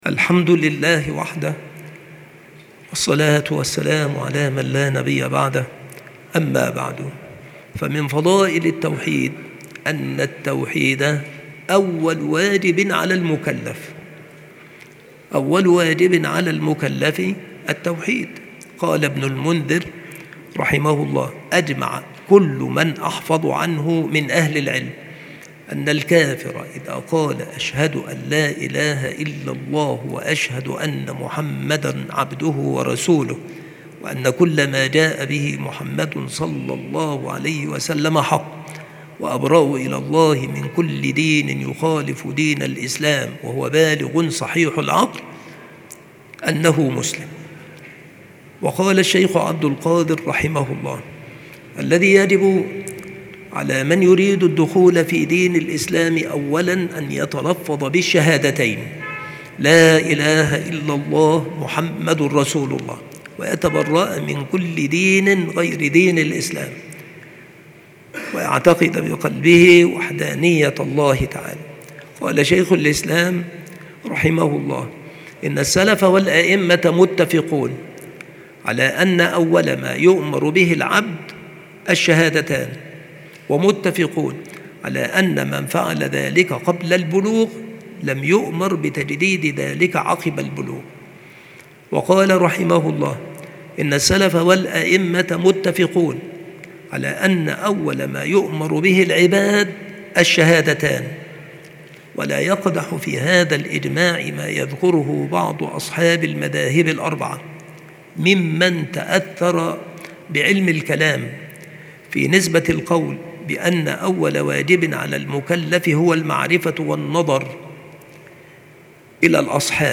بالمسجد الشرقي - سبك الأحد - أشمون - محافظة المنوفية - مصر